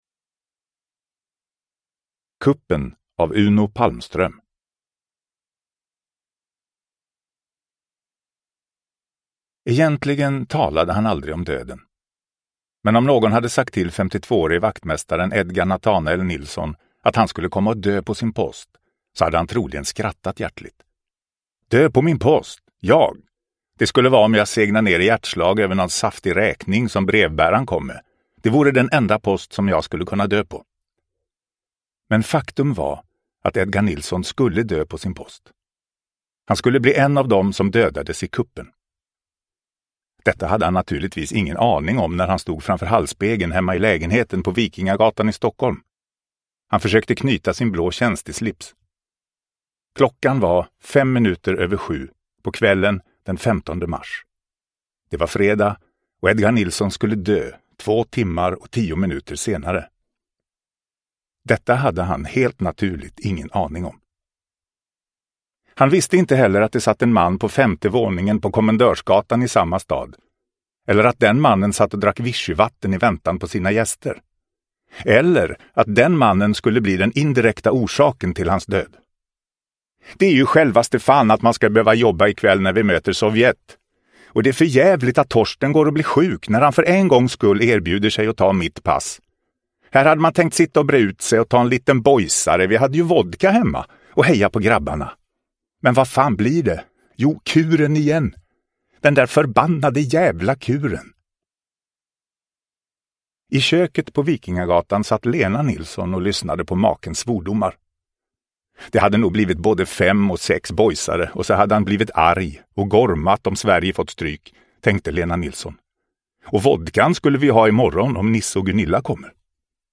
Kuppen – Ljudbok – Laddas ner